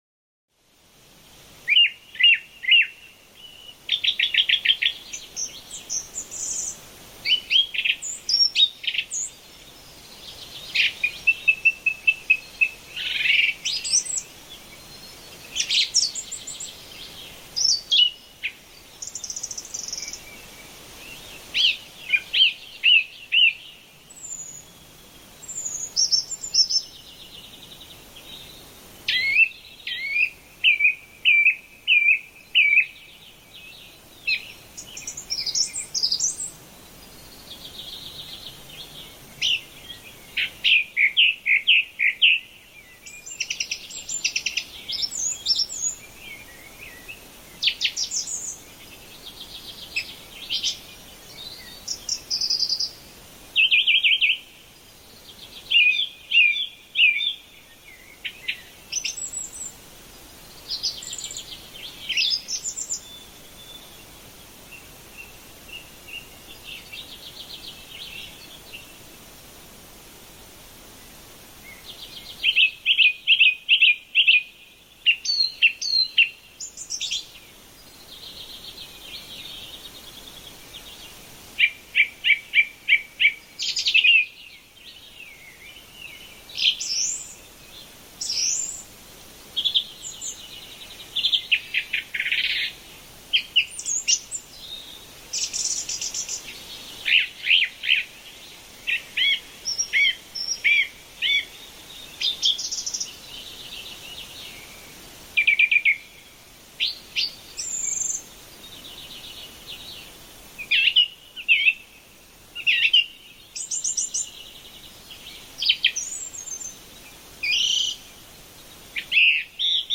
FRIEDENS-LANDSCHAFT: Blumenwiesen-Vogelstimmen in stiller Natur